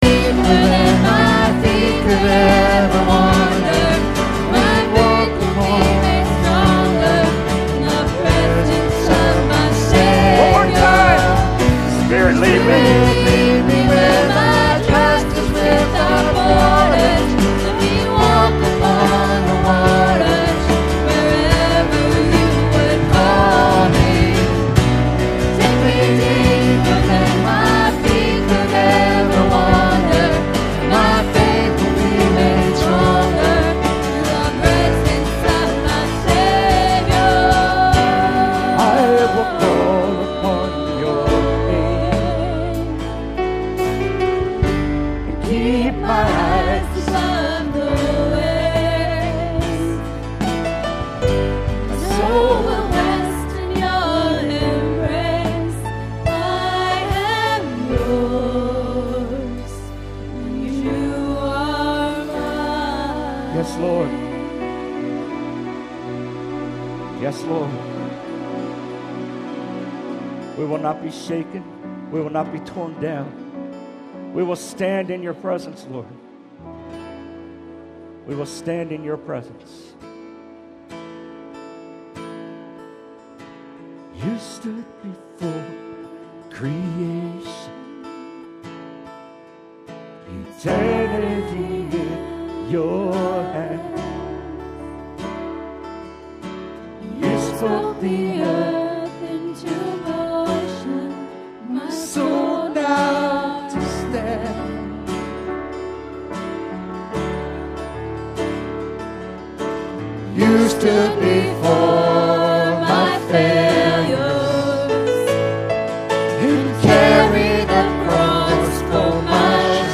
It's rare for us to be able to share video from a Sunday morning Encounter Service.